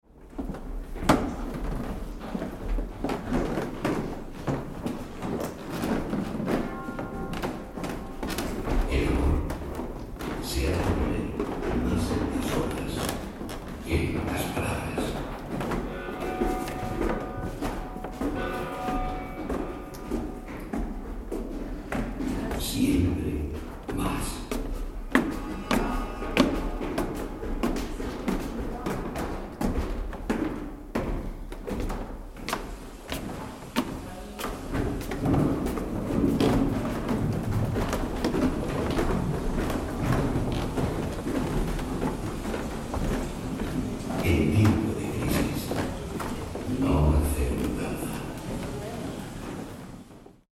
The descent from Salamanca’s Clerecía Towers unfolds like an auditory time capsule, where each step resonates with the weight of history. The recording begins at the heights of this iconic cathedral, capturing the faint echoes of voices carried by the lofty ceilings before transitioning into the measured rhythm of footsteps descending the spiral staircase. As the journey unfolds, subtle hints of the past emerge in the form of audio guides weaving through the air - snippets of historical narratives blending with the natural acoustics of the stone walls.
From the awe-inspiring heights to the grounded pulse of everyday Salamanca, the recording encapsulates the beauty of transition, where echoes of the sacred meet the drone of the ordinary.